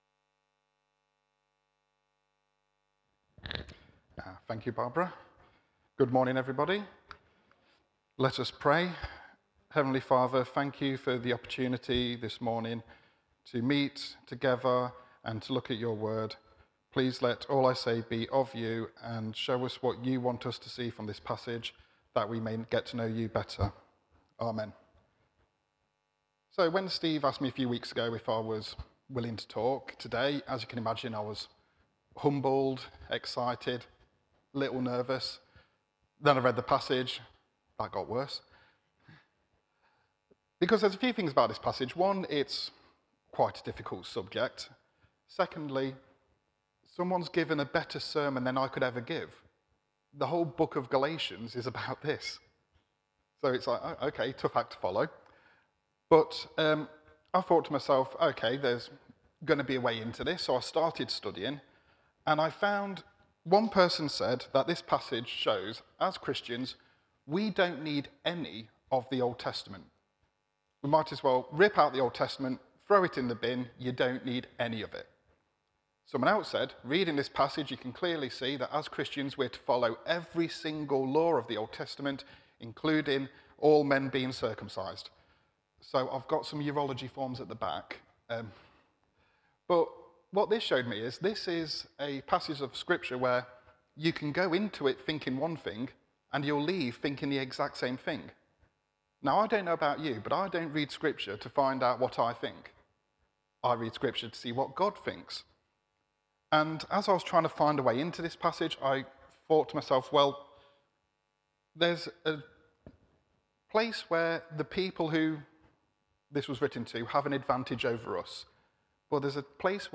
Media Library The Sunday Sermons are generally recorded each week at St Mark's Community Church.
Theme: The Jerusalem Council Sermon